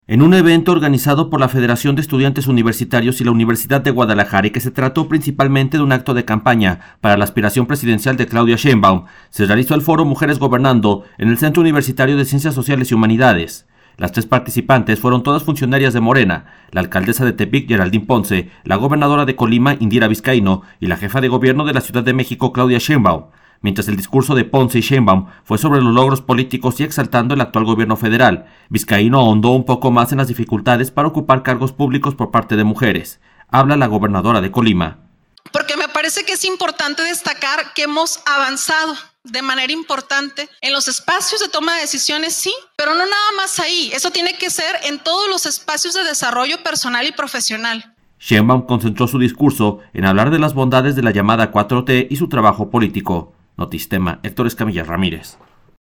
En un evento organizado por la Federación de Estudiantes Universitarios y la Universidad de Guadalajara y que se trató principalmente de un acto de campaña para la aspiración presidencial de Claudia Sheinbaum, se realizó el foro Mujeres Gobernando en el Centro Universitario […]